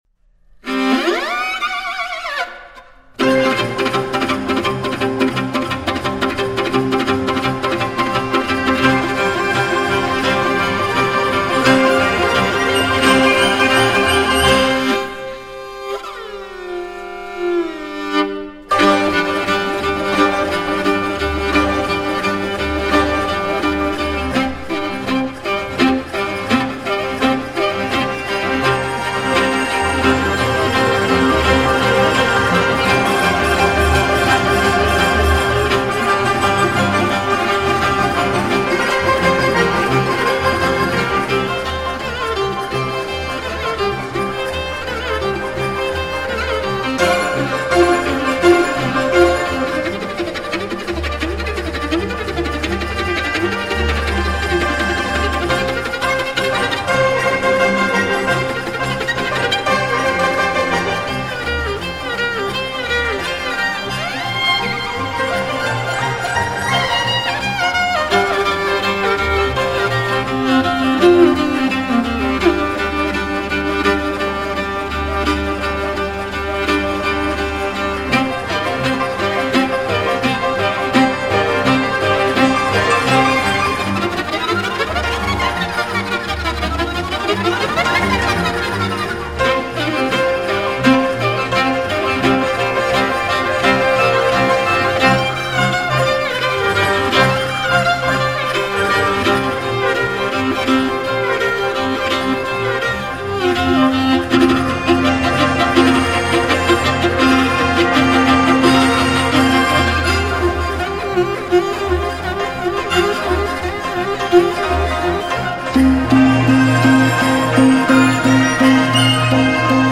马头琴